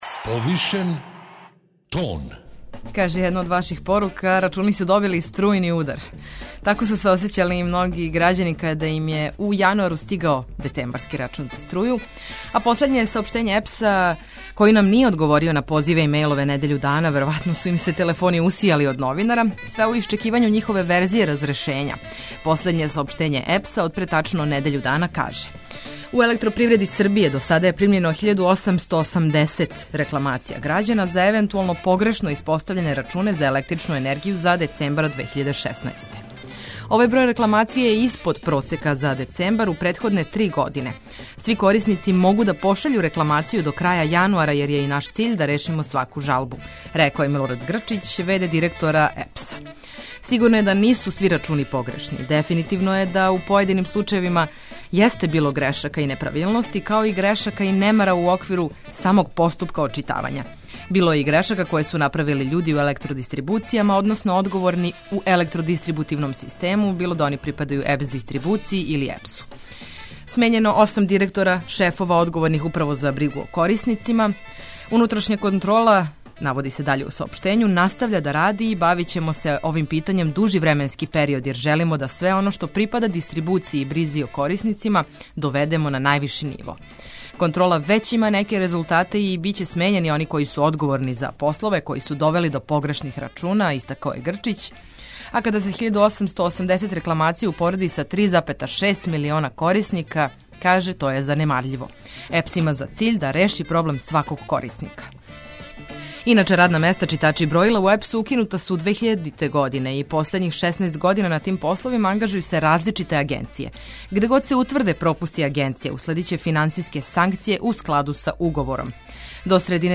Питали смо и грађане да ли их збуњују рачуни за струју, а понудићемо и скице за тумачење овог важног месечног трошка.